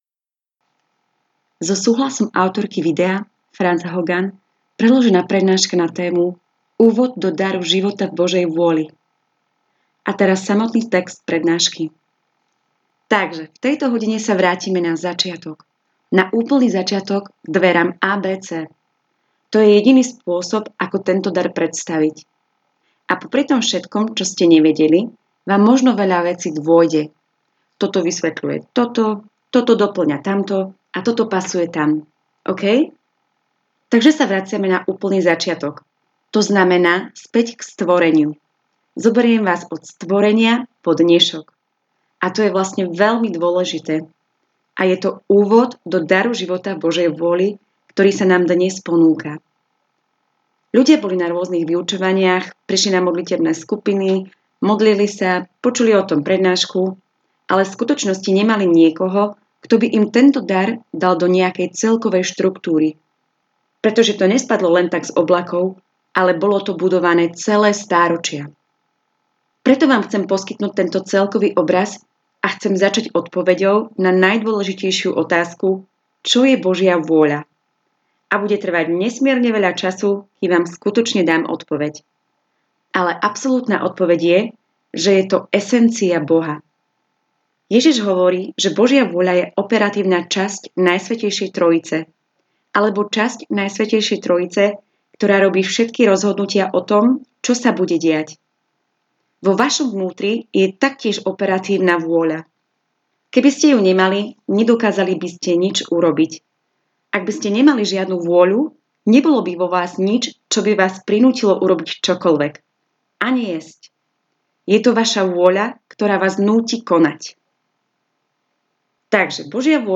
Tyto přednášky Vám dají stručný obraz toho, o čem dar života v Boží vůli vlastně je.